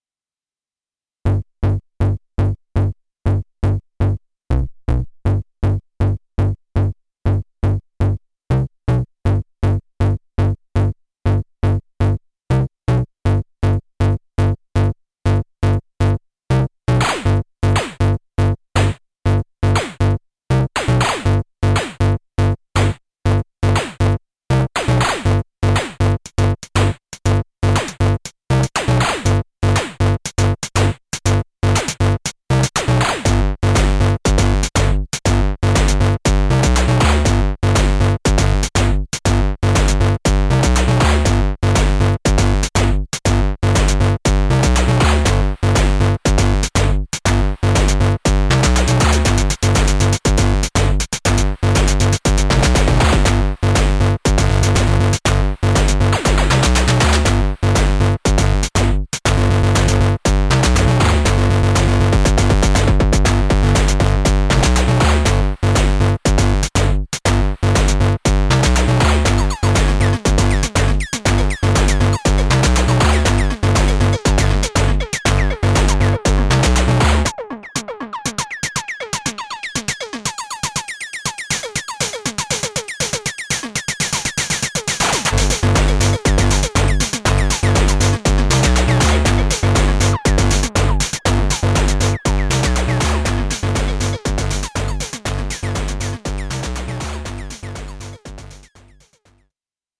All sequenced from Octatrack, including chromatic notes, midi-arp, midi-lfo, midi-retrig on that bleep snare,… (no lfo in lxr-kit!). I used the faders and performance buttons (mute and ta ta ta ta ta) on the lxr.
It is sometimes laggy and misses cc values…, but I guess if I put a delay into it or so… perhaps, perhaps, I’ll investigate.
Erica Synths LXR-02 Desktop Digital Drum Synthesizer